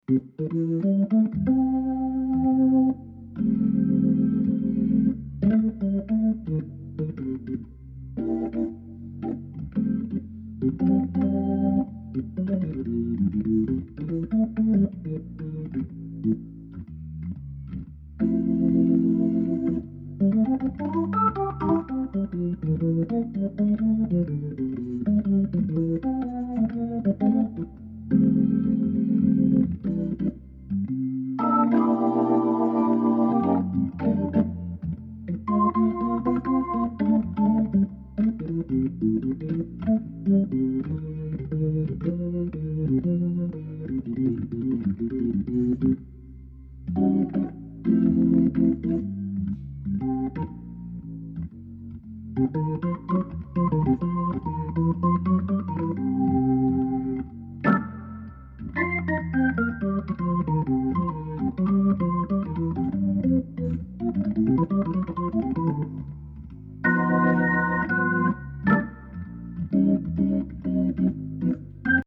Cette fois pas d'organiste à decouvrir c'est moi en solo pour la musique de generique d'hammond inside....mais voilà.....quel instrument?
122 c'est sûr, elle vibre encore!!!
en fait ce qui vibre c'est l'horloge hammond en metal que j'avais oublié d'enlever du dessus de la cabine.....donc ça reste une vibration acceptable :)
Je pense que c'est le XK system (key click systematique).
En attendant, ça swingue...et ça pulse....